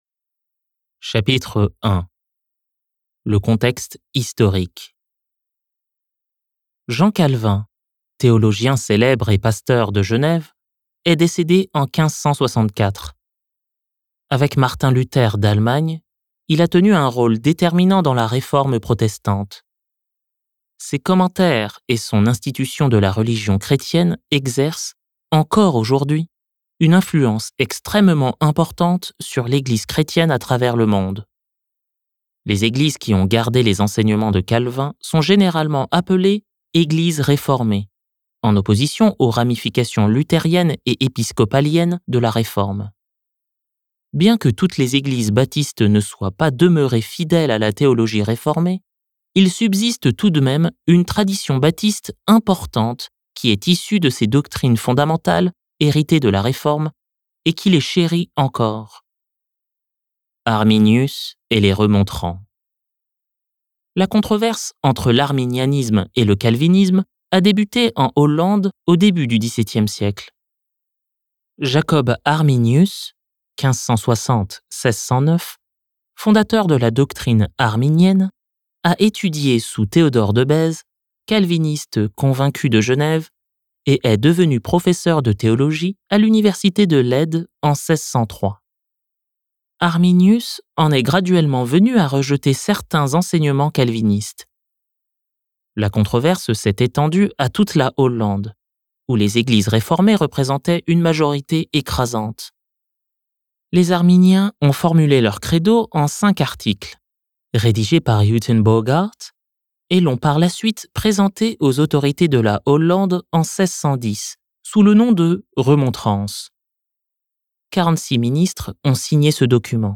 Les cinq points du calvinisme (livre audio)